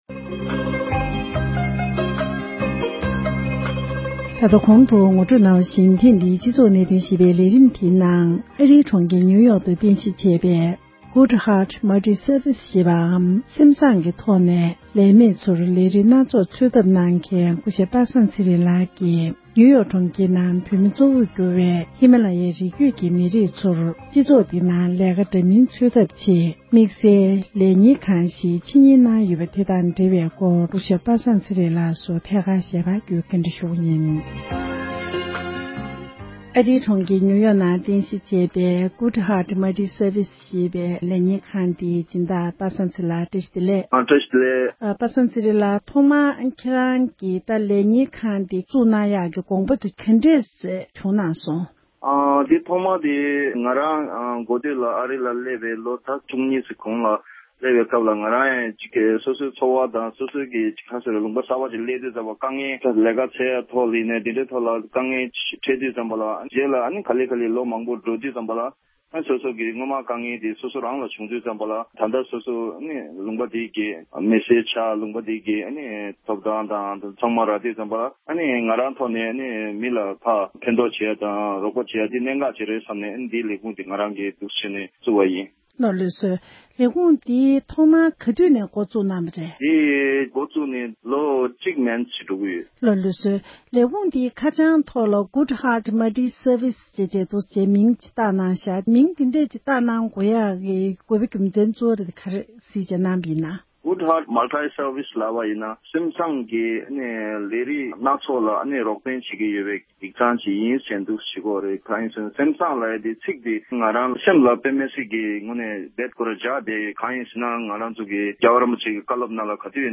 ཞལ་པར་བརྒྱུད་ཐད་ཀར་གནས་འདྲི་ཞུས་པ་ཞིག